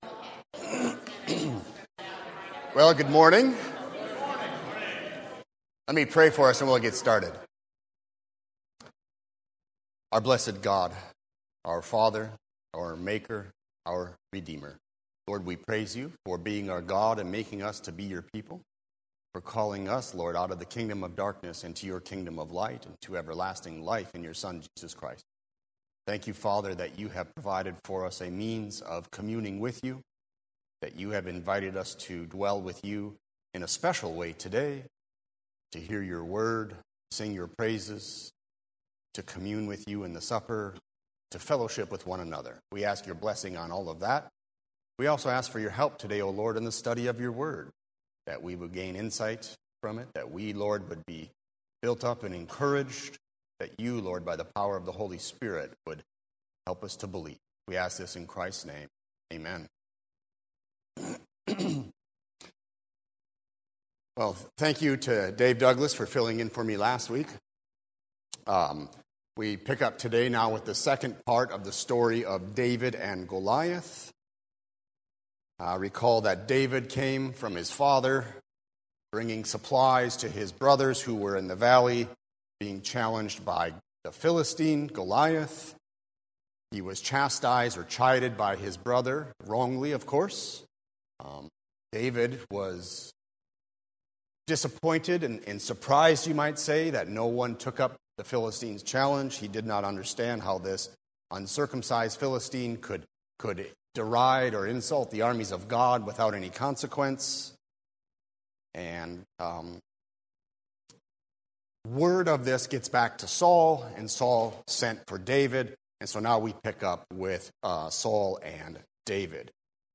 00:00 Download Copy link Sermon Text Believing in Jesus Christ as the eternal Son of God